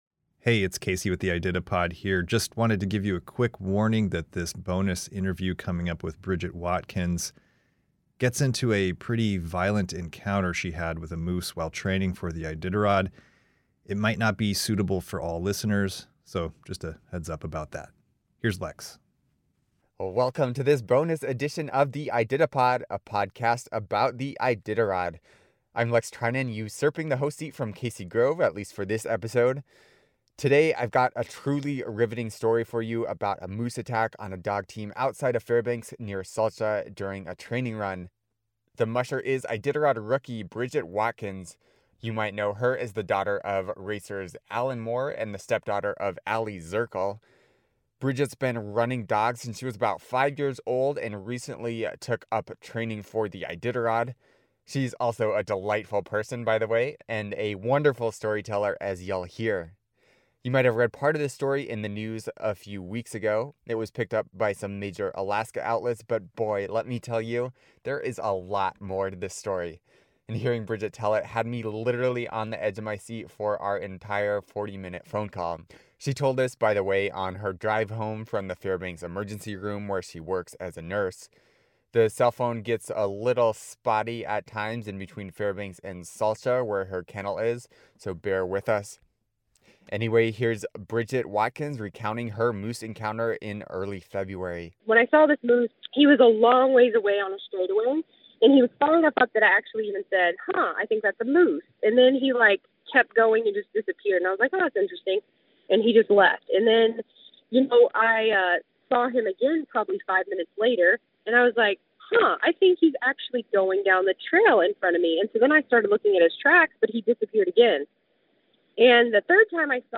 **Warning: This extended interview discusses a violent encounter with a moose and might not be suitable for all listeners.